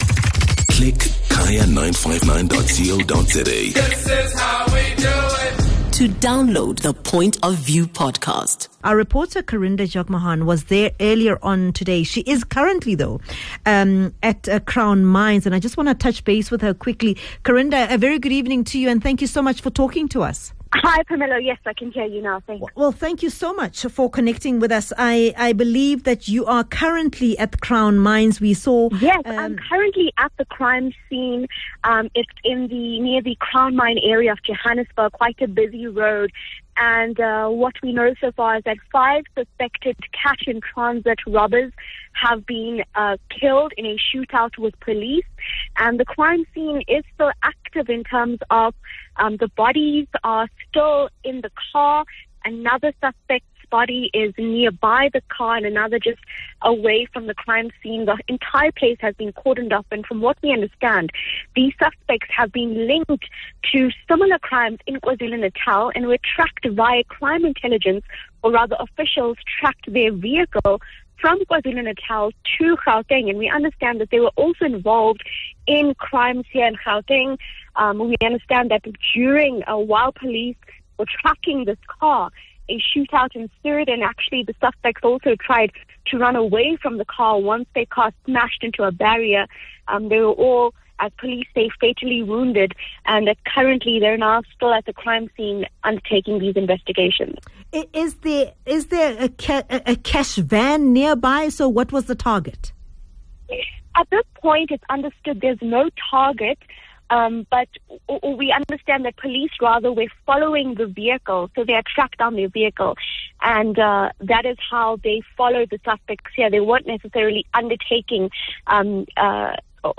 The suspects, heavily armed, opened fire as police closed in. Kaya News Reporter